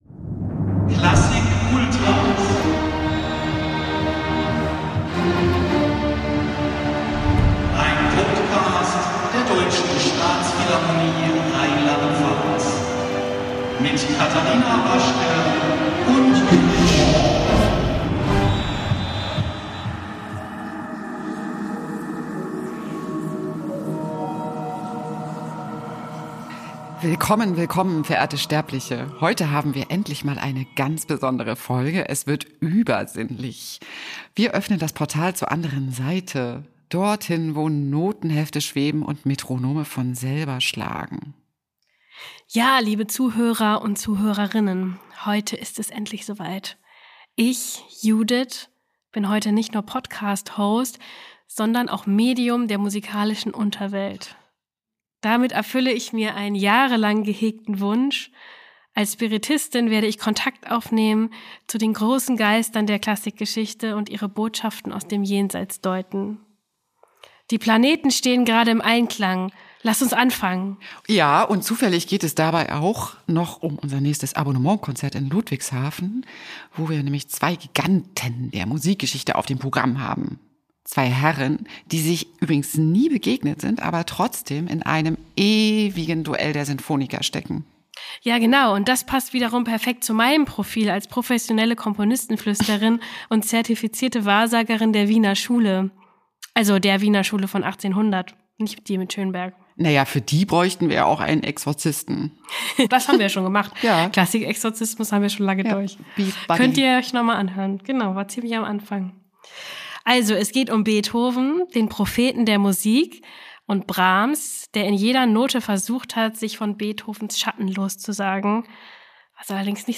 In nicht immer zielorientierten, dafür von Faszination und Begeisterung getriebenen Dialogen berichten sie über Begebenheiten, die mit klassischer Musik und dem wahren Leben zu tun haben. Musikgeschichtliche Fakten haben darin ebenso einen Platz wie skurrile Geschichten – fast immer entspricht das Gesagte der Wahrheit.